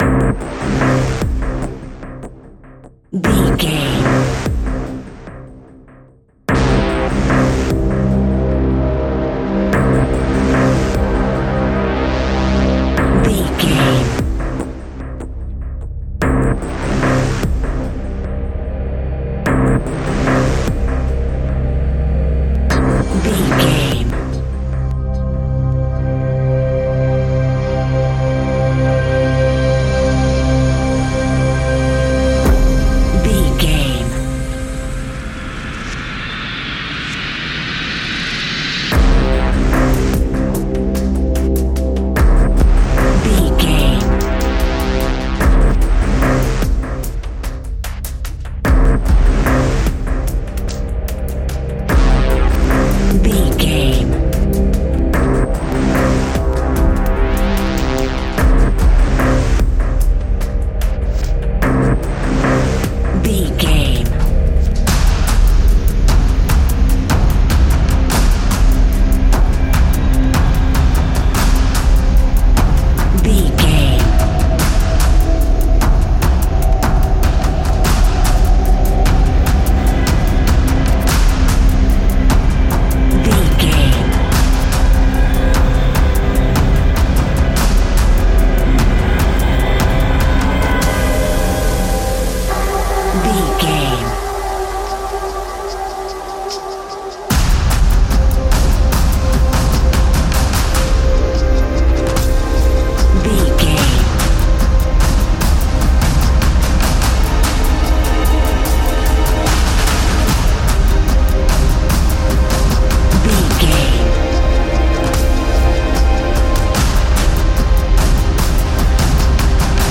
Fast paced
In-crescendo
Ionian/Major
industrial
dark ambient
EBM
synths
Krautrock